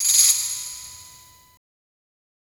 Metro Big Tambourine .wav